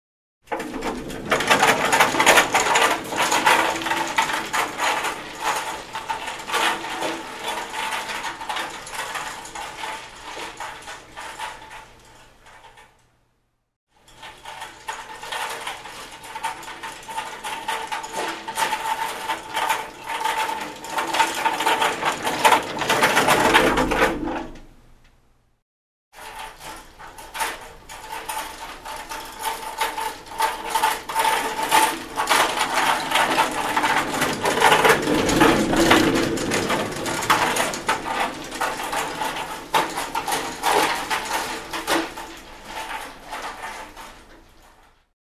Кушетка, транспортировка больного